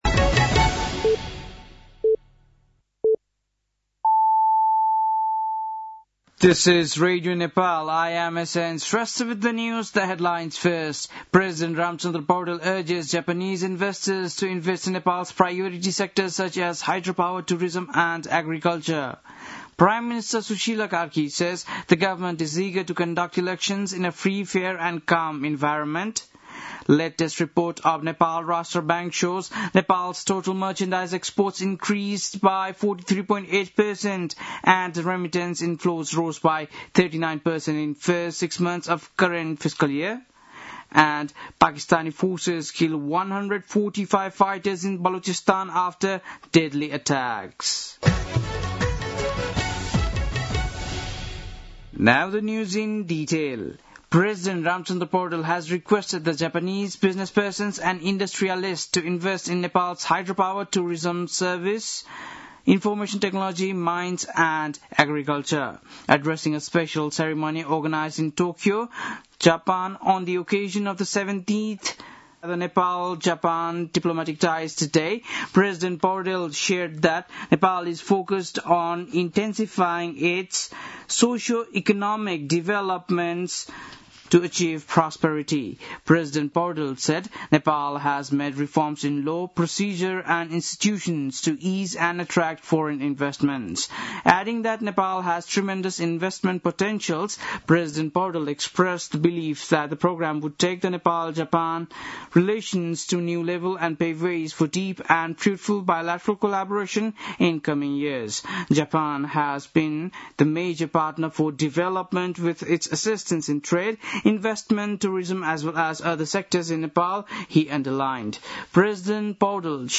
बेलुकी ८ बजेको अङ्ग्रेजी समाचार : १९ माघ , २०८२
8-pm-english-news-10-19.mp3